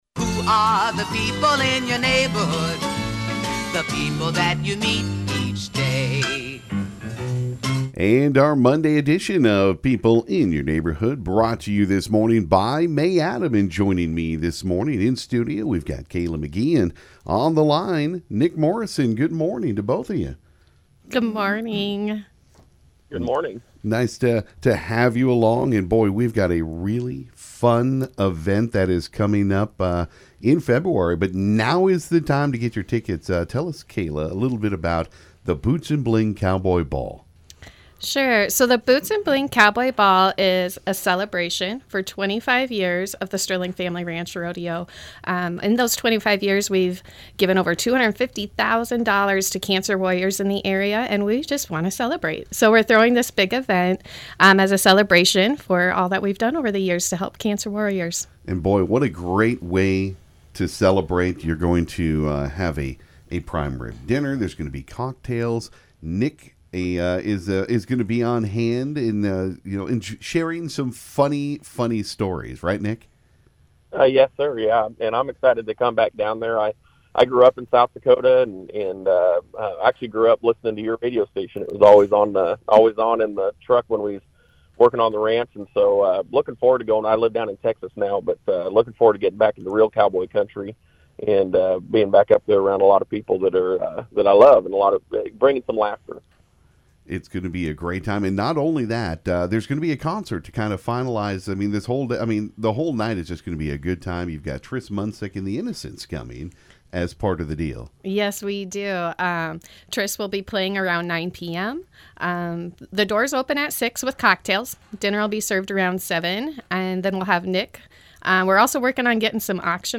Boots & Bling Cowboy Ball interview